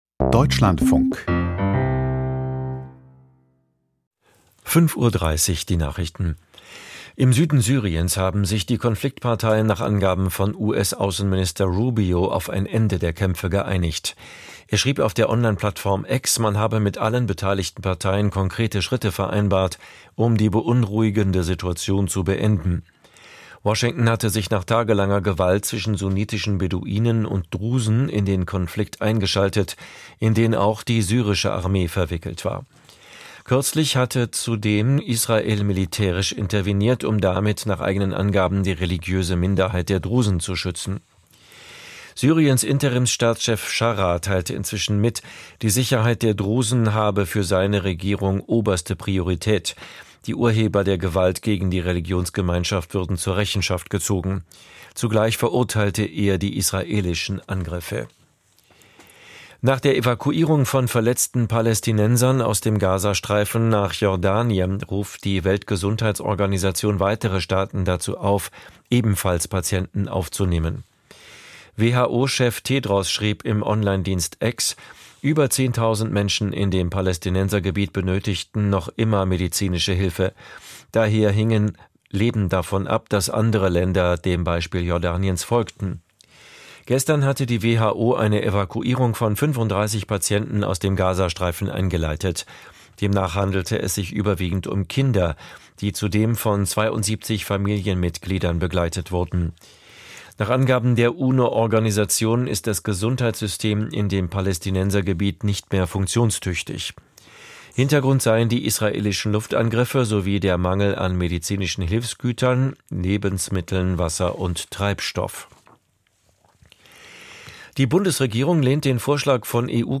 Die Nachrichten vom 17.07.2025, 05:30 Uhr
Aus der Deutschlandfunk-Nachrichtenredaktion.